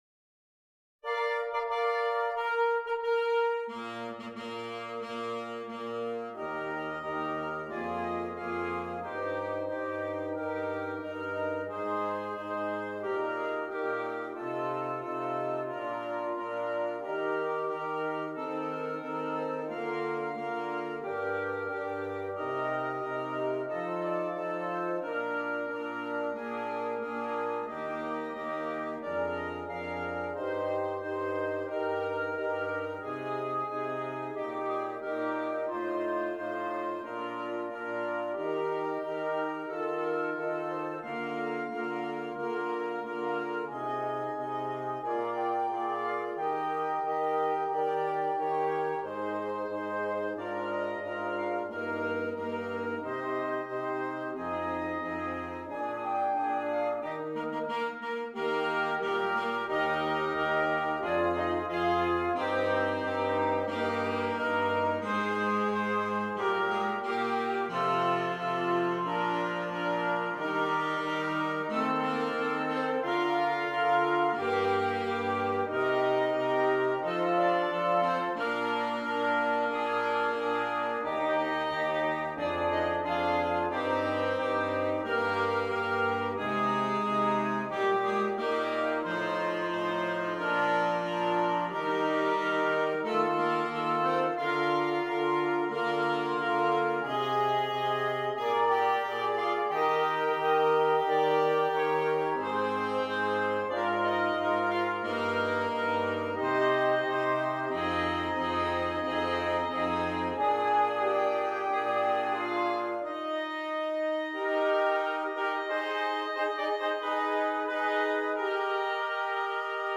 Interchangeable Woodwind Ensemble